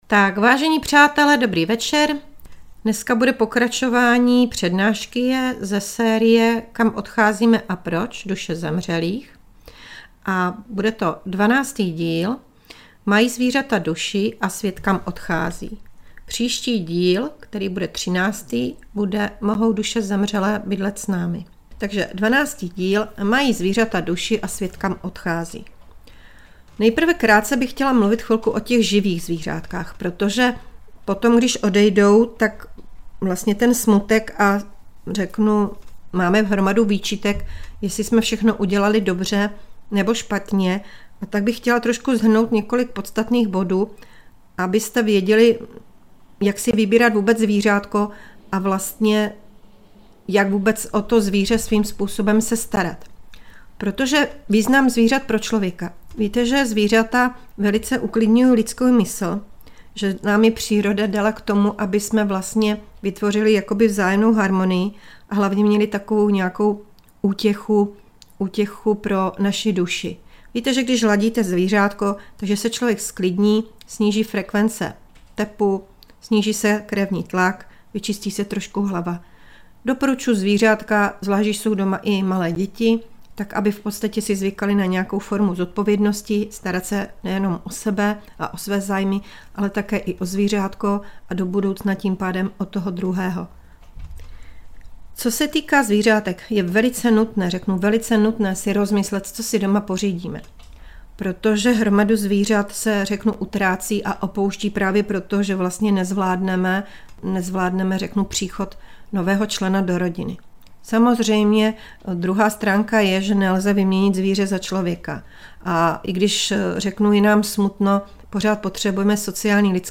Přednáška Duše zemřelých, díl 12. - Mají zvířata duši?